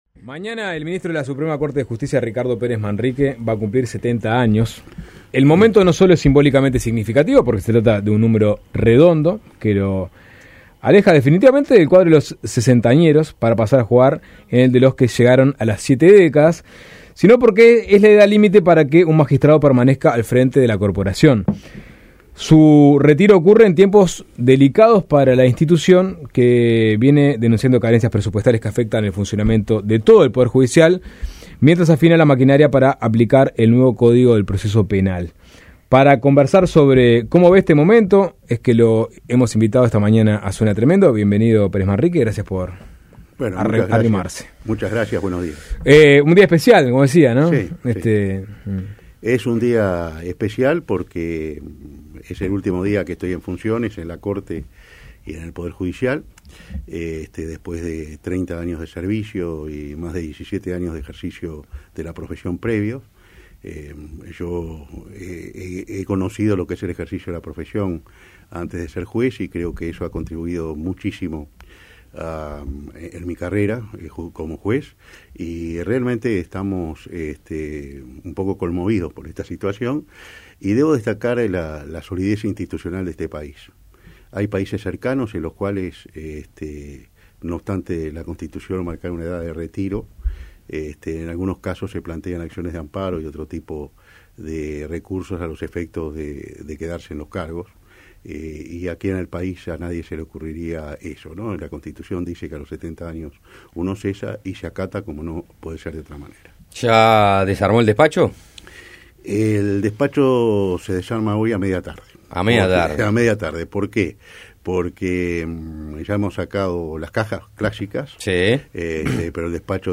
Descargar Audio no soportado En entrevista con Suena Tremendo, el magistrado explicó que el argumento de que hacen falta algunas modificaciones legislativas para poner a funcionar el código, como manejó hace unos días el Fiscal de Corte -Jorge Díaz-, no le parece suficiente.